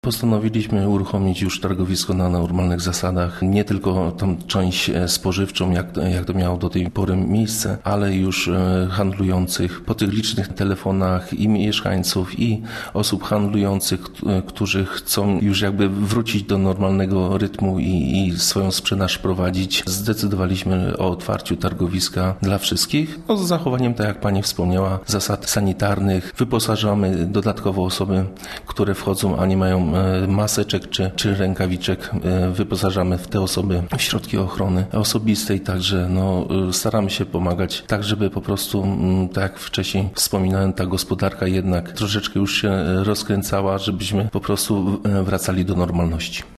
Postanowiliśmy już uruchomić targowisko na normalnych zasadach – informuje Rafał Drab, Burmistrz Miasta i Gminy Działoszyn.